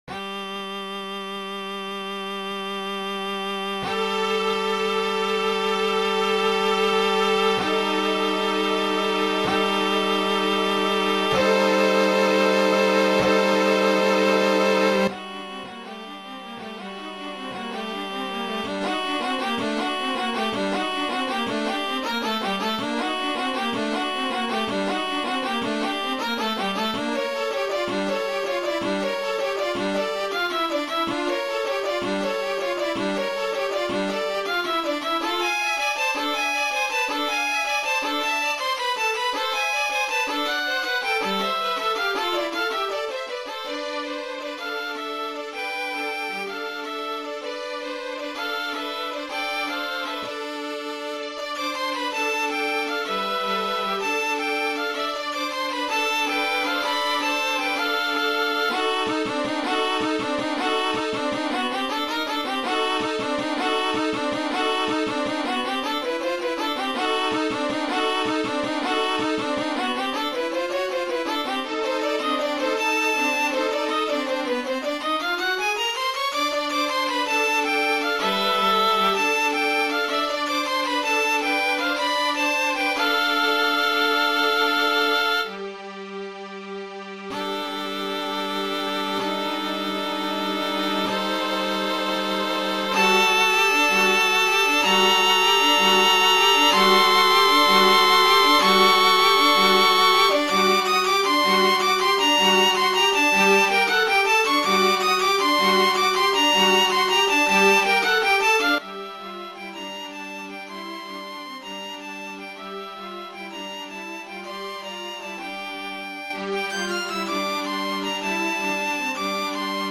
Das MP3 wurde von Finale erstellt und klingt furchtbar.